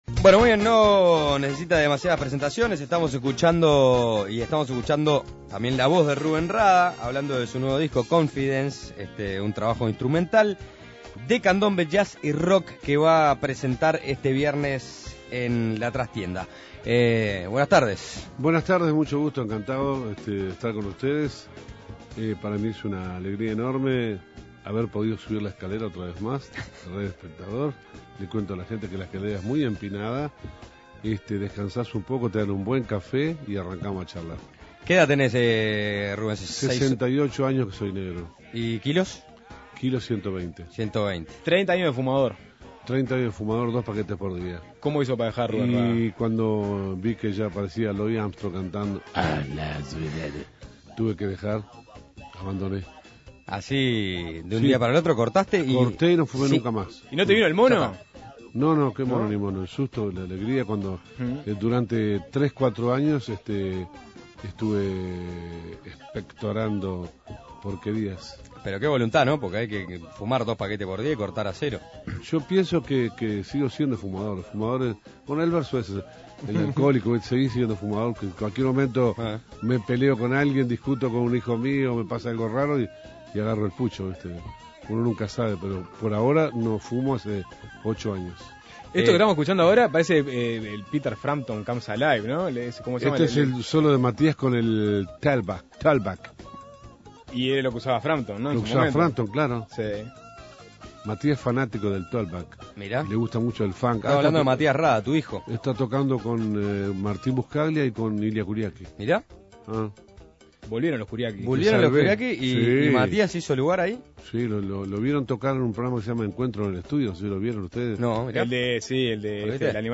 La recta final del año recibe bastante ocupado a Rúben Rada ya que además de presentar su nuevo album "Confidence", en Argentina y el próximo viernes en la Trastienda en Uruguay; deberá viajar a Las Vegas en noviembre para ser el primer uruguayo en obtener el Premio Grammy Latino a la excelencia musical. En una charla distendida Suena Tremendo dialogó esta tarde con Rúben Rada.